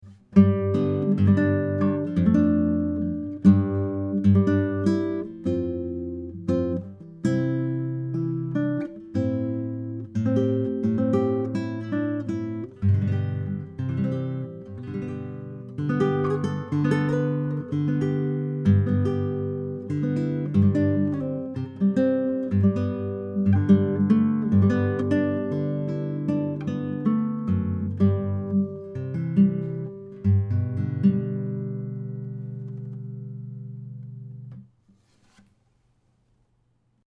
Classical
This is a classical guitar with a sitka spruce soundboard and Indian Rosewood sides and back.
It has very lovely tone and nice volume and projection.